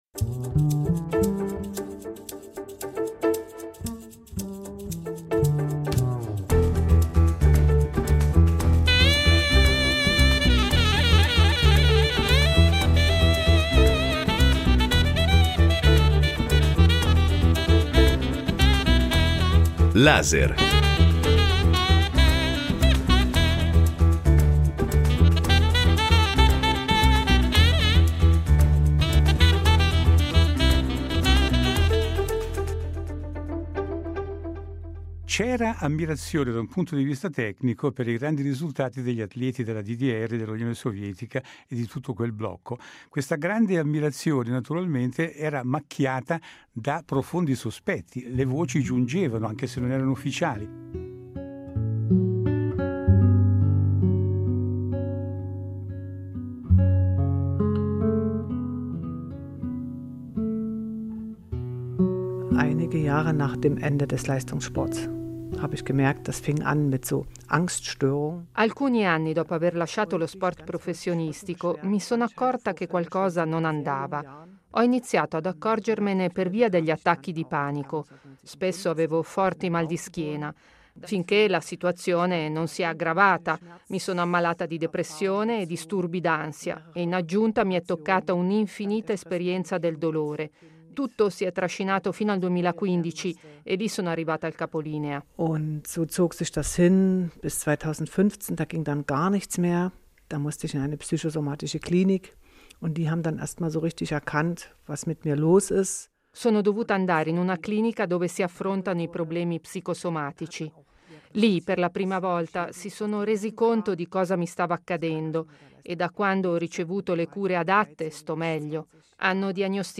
Sara Simeoni , oro nel salto in alto a Mosca 1980